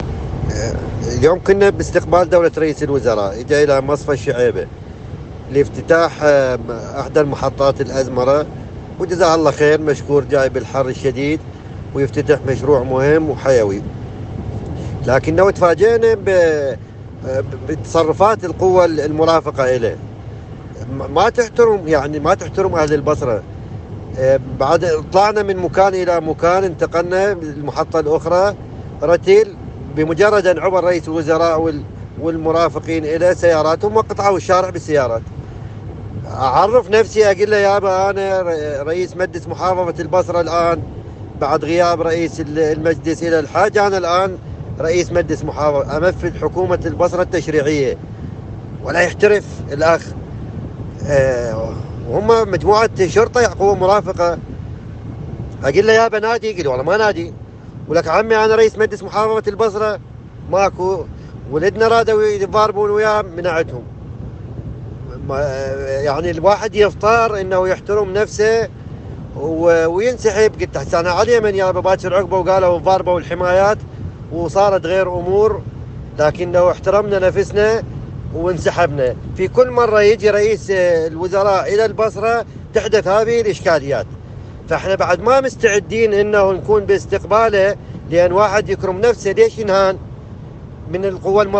تسجيل بصوت رئيس مجلس البصرة: كدنا نشتبك مع حماية السوداني!